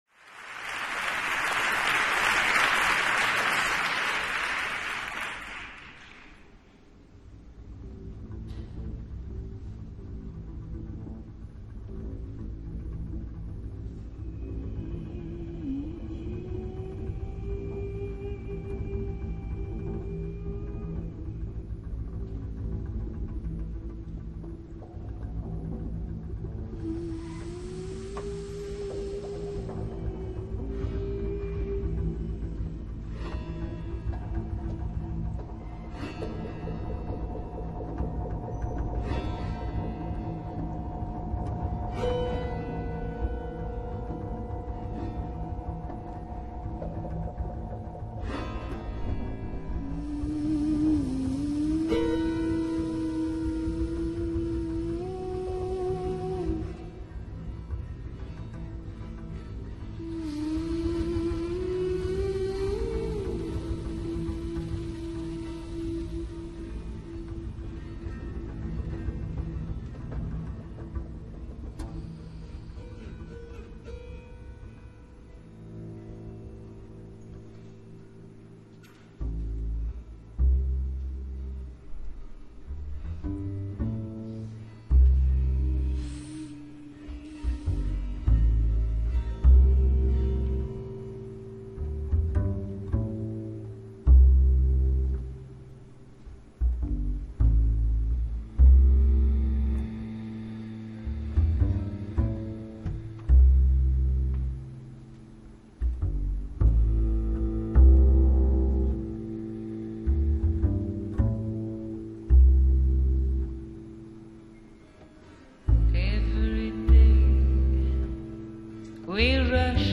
in concert at Route du Rock Winter
Belgian/Italian singer
has been described as a dark and sultry rhythmic incantation
Stark emotion in an ethereal world.